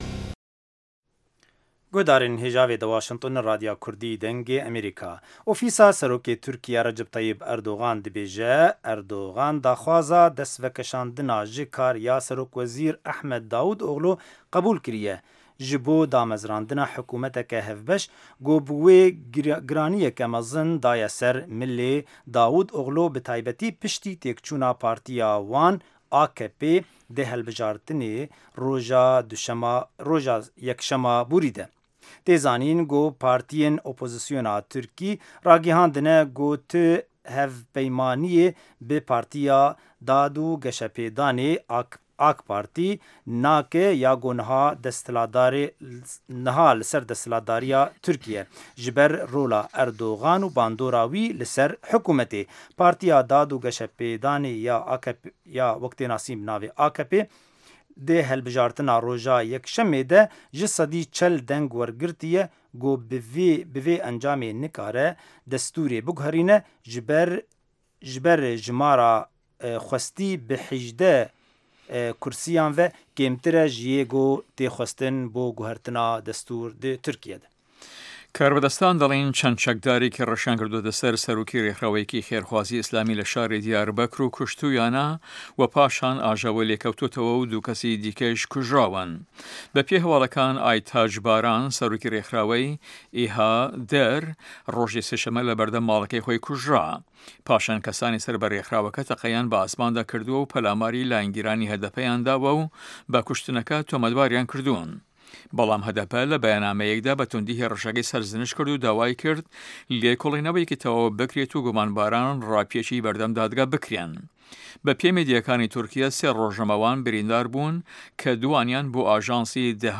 هه‌واڵه‌کان، ڕاپـۆرت، وتووێژ، سه‌رگوتاری ڕۆژانه‌‌ که‌ تیایدا ڕاوبۆچوونی حکومه‌تی ئه‌مه‌ریکا ده‌خرێته‌ ڕوو.